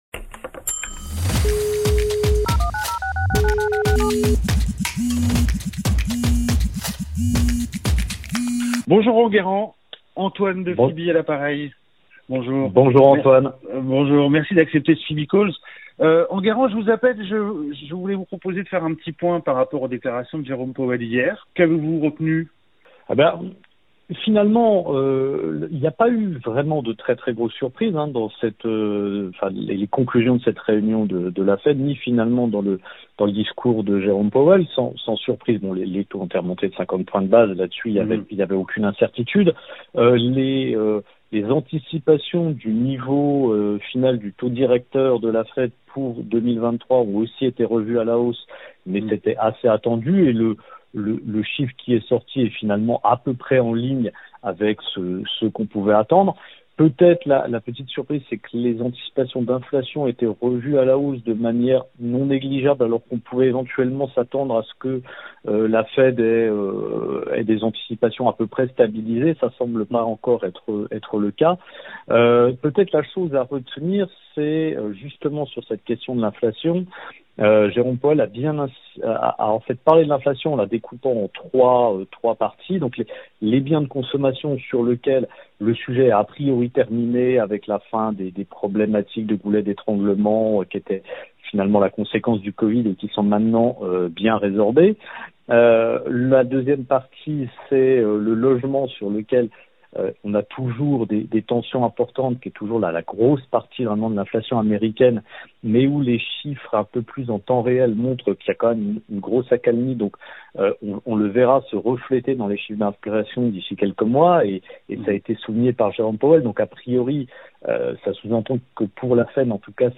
Un coup de fil